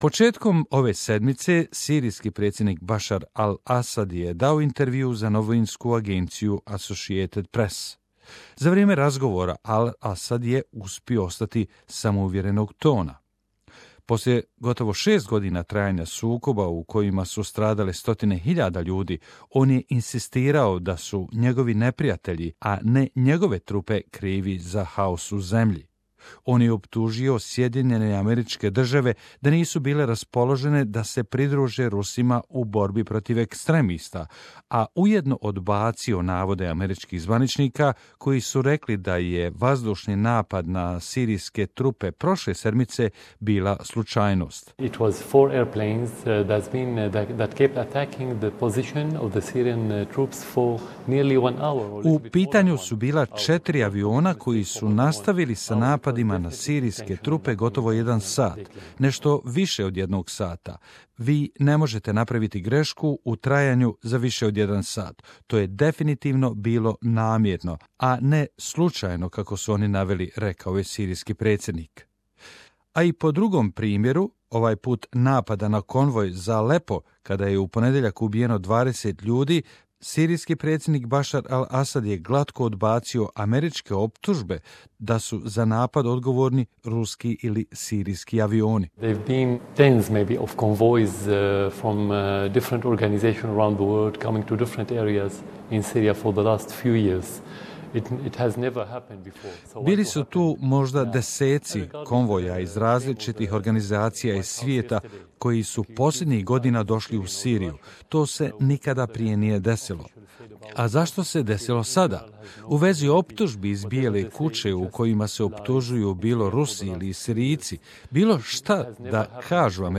'Doomsday, hell itself': Aleppo resident describes intensified attacks